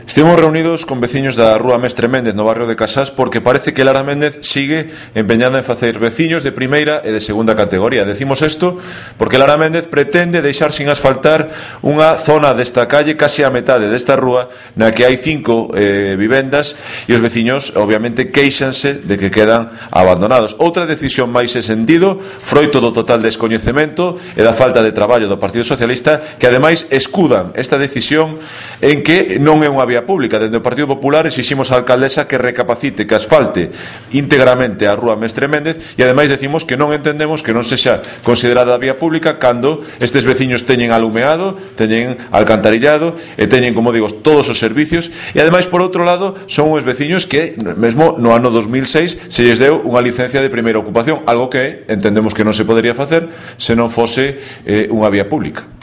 Corte-Ameijide-rúa-Mestre-Méndez.mp3